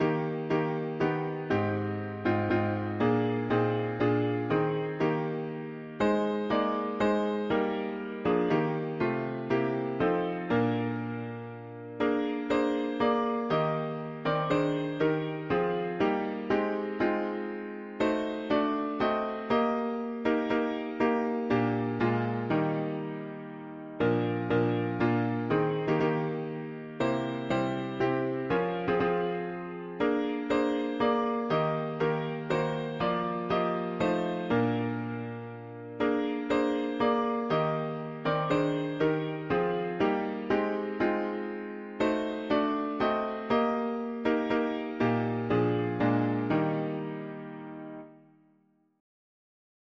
As thou hast be… english theist 4part
Key: E-flat major